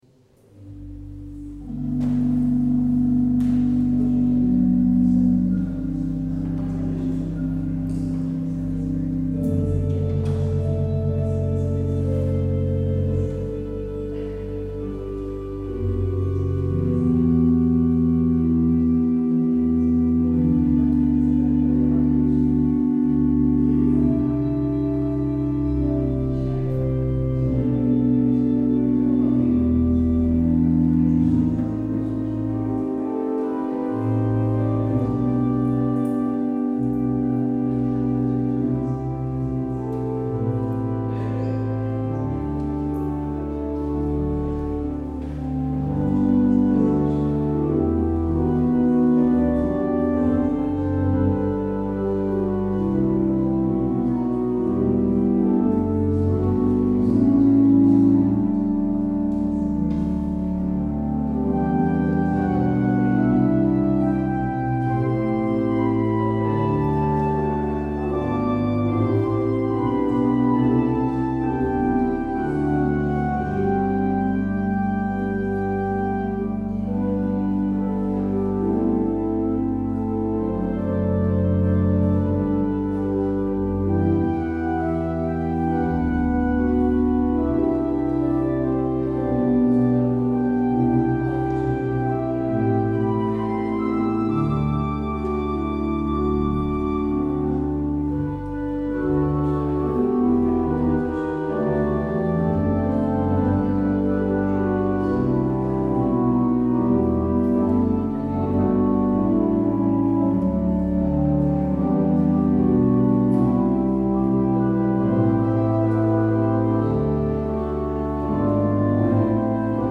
 Luister deze kerkdienst hier terug: Alle-Dag-Kerk 3 oktober 2023 Alle-Dag-Kerk https